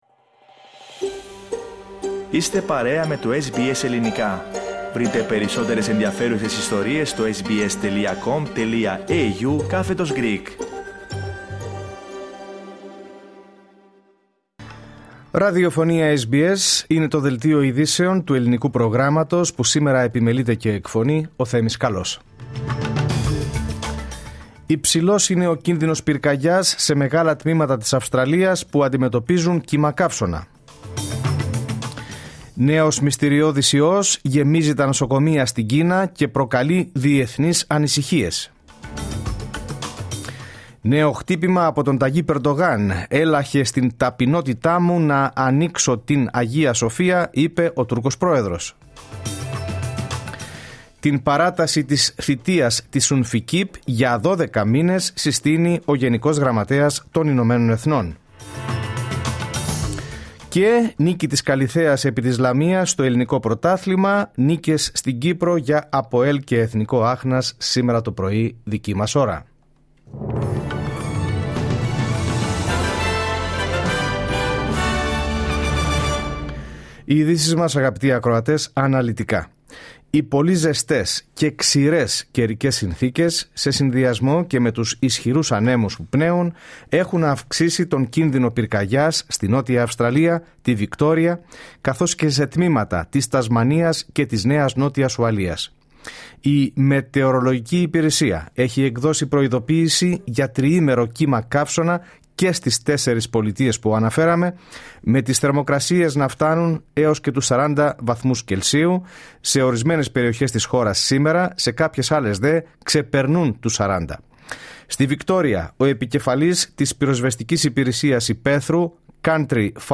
Δελτίο Ειδήσεων Κυριακή 05 Ιανουαρίου 2025 12'.08''